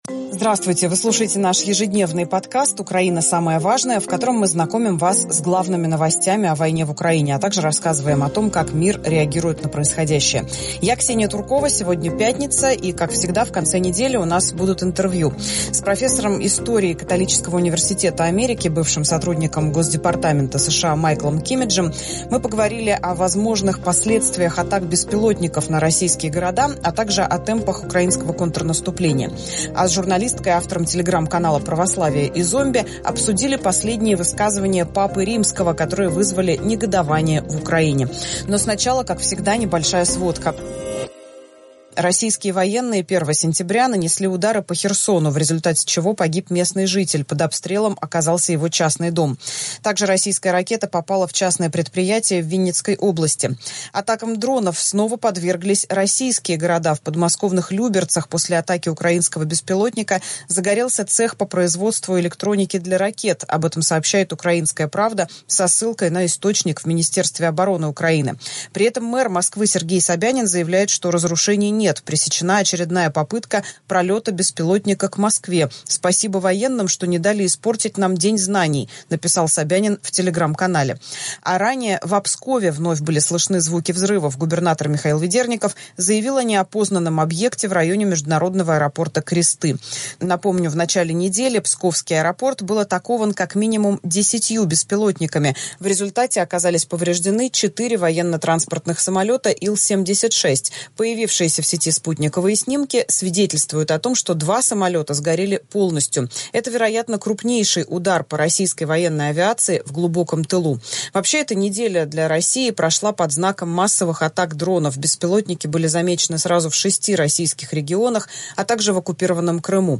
Как всегда, в пятницу наш подкаст выходит в формате интервью.